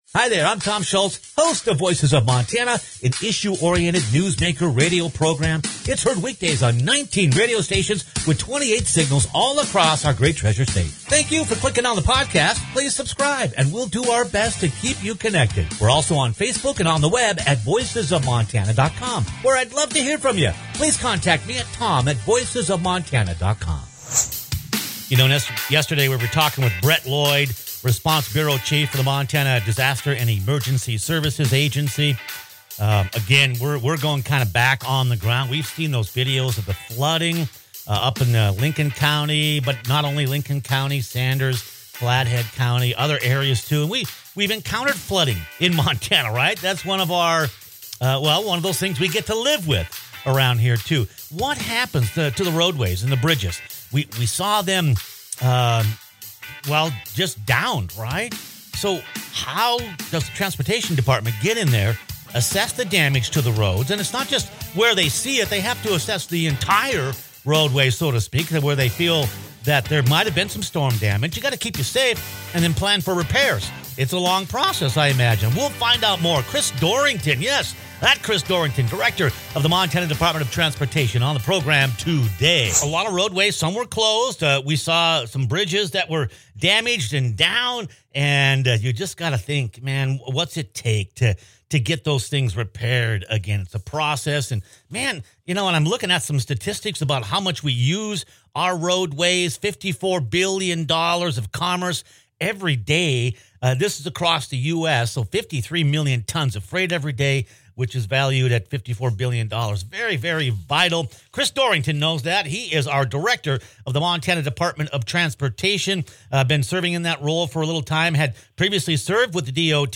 When roadways and bridges are impacted by some of Montana’s harshest weather conditions, how does the transportation department assess the damage, keep you safe, and plan for repairs? Click on the podcast as special guest Chris Dorrington, Director of the Montana Department of Transportation, discusses those questions and updates us on the recent storm damage and